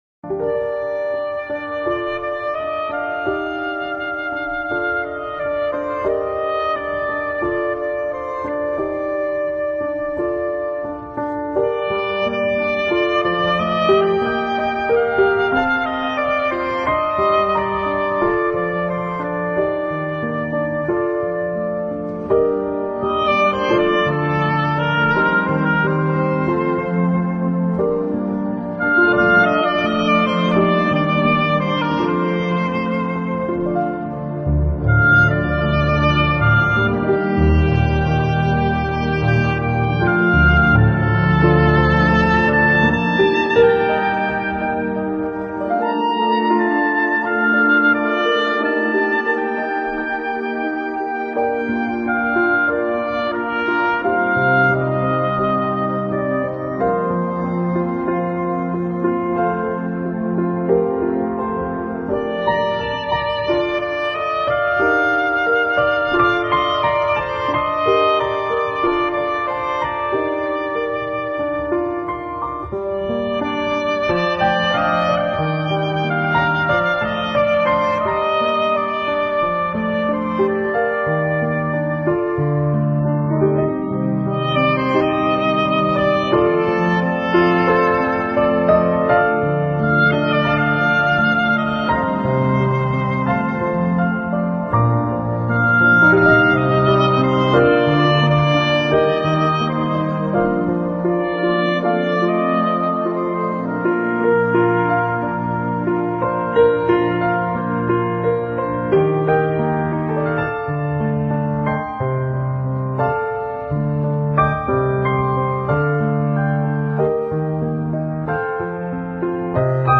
【新世纪音乐】
依然一样的旋律，悠长的长笛和排箫，清脆的钢琴，孤独而傲然地穿梭在音乐
来特别舒服和祥和。